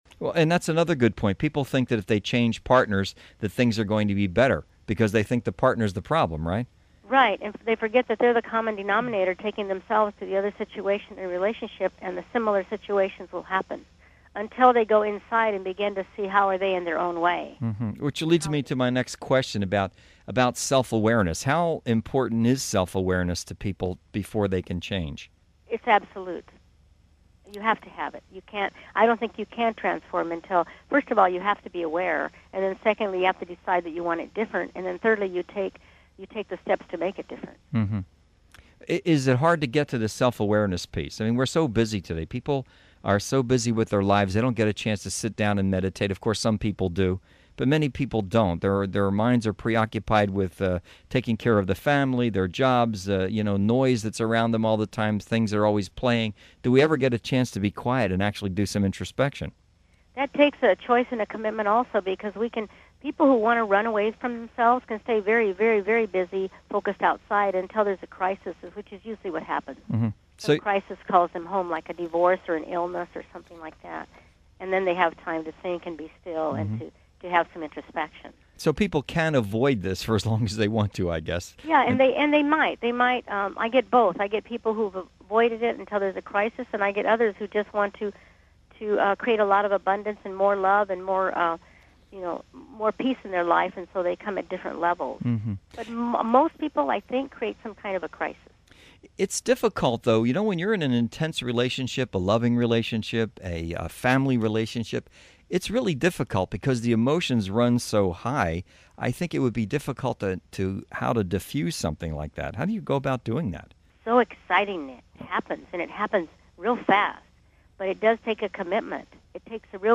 "How to Love the People You Hate" Radio Interview